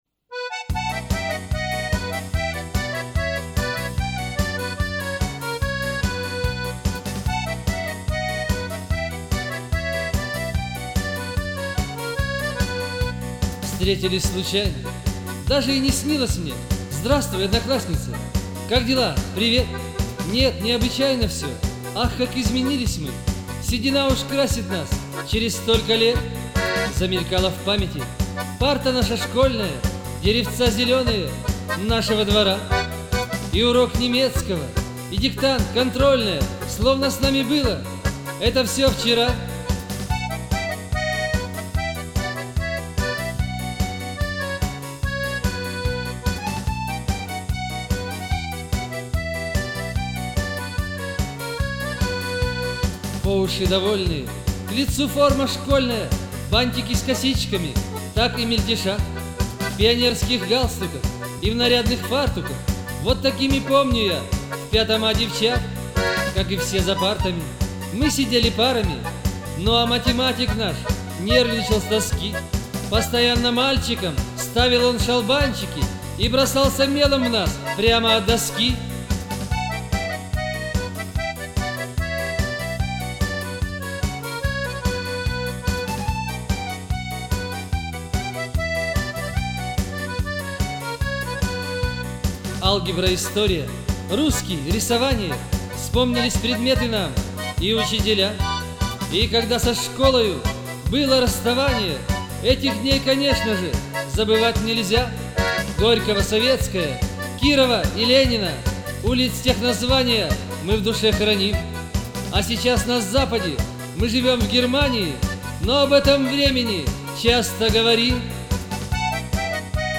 Главная » Файлы » POP [ Добавить материал ]